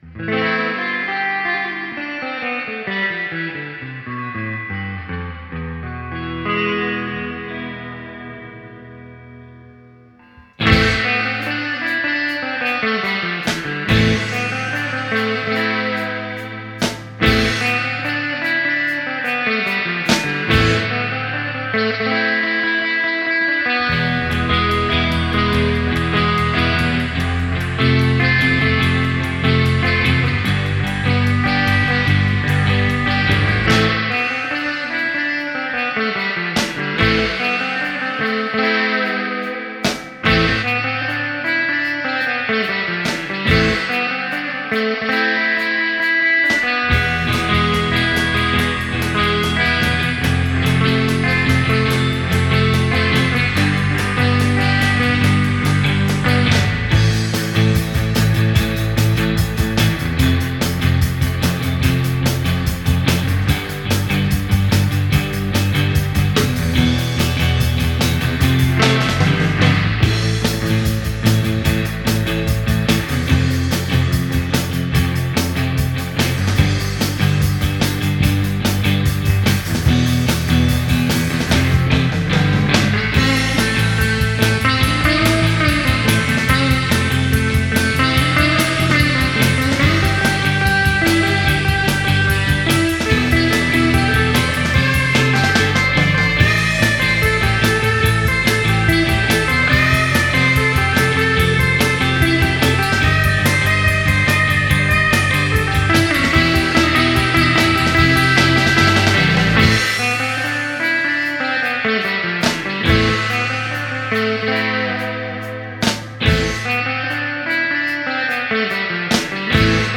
Rock
Instrumental Surf Rock
I particularly like the lead guitar parts on this song.
like the spanish flair
The guitars indeed sound nice.